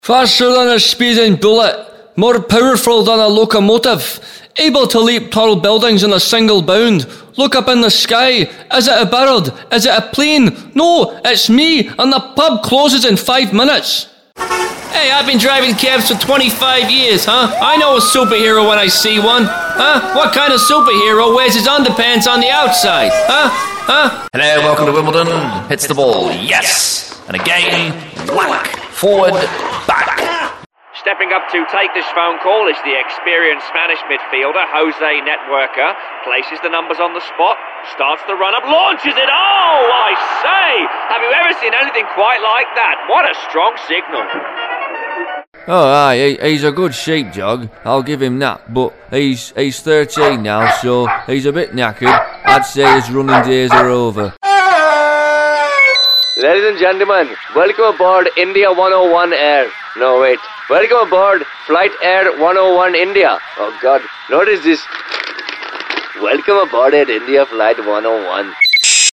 Sprecher englisch (usa) Dialekte - All UK regions, most European countries, USA, Deep South, New York, Australian, Hebrew, Indian, Russian, Scottish, Welsh, Irish
englisch (uk)
Sprechprobe: eLearning (Muttersprache):